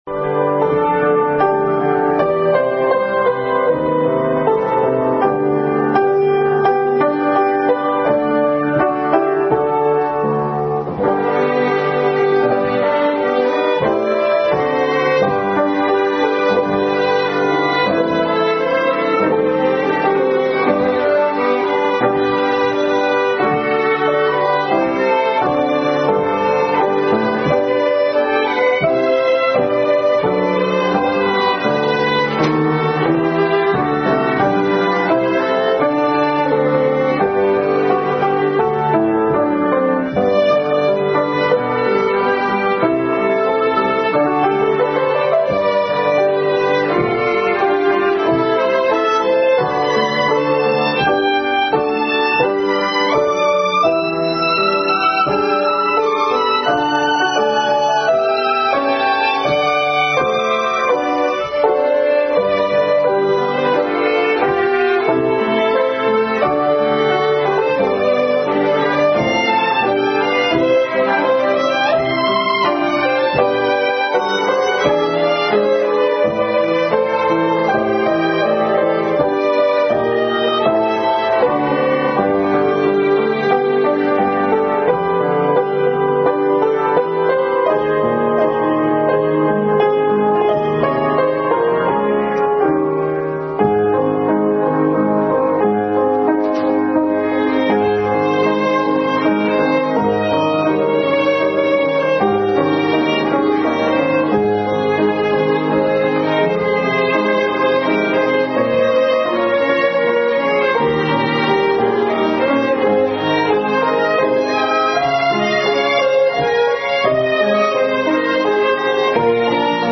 Family Bible Hour Message preceded by special Music.